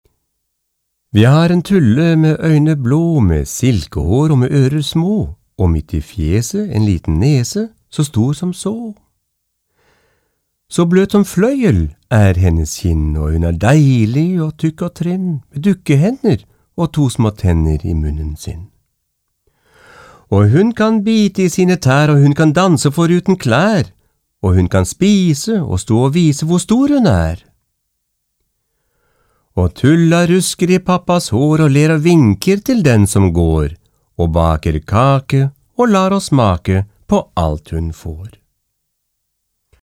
Sprecher Norwegisch.
Kein Dialekt
Sprechprobe: Sonstiges (Muttersprache):
norvegian voice over artist